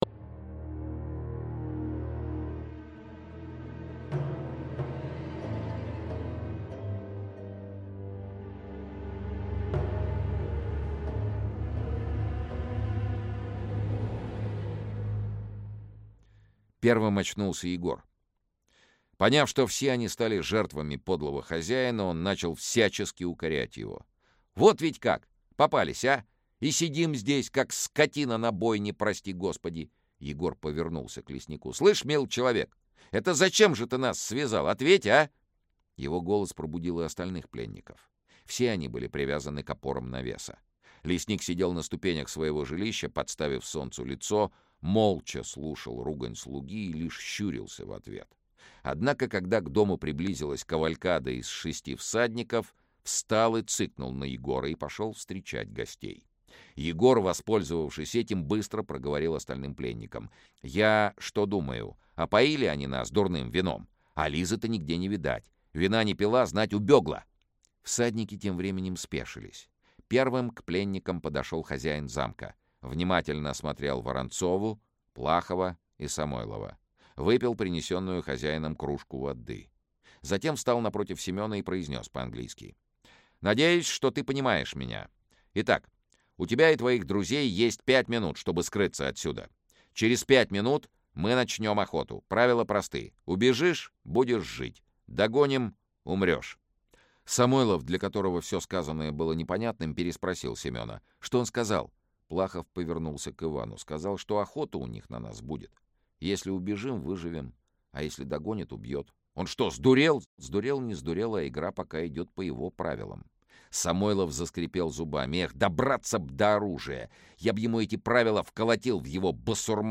Аудиокнига Нерассказанные истории. Охота | Библиотека аудиокниг
Aудиокнига Нерассказанные истории. Охота Автор Олег Рясков Читает аудиокнигу Сергей Чонишвили.